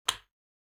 buttonpress.wav